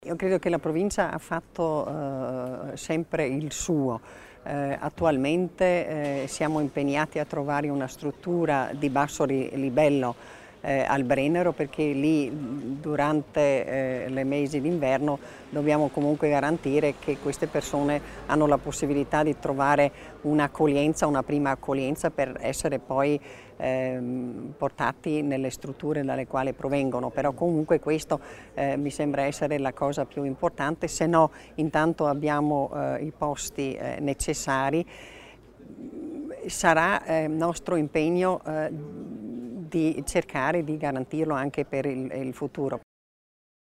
Il Presidente Kompatscher spiega la politica di accoglienza per i profughi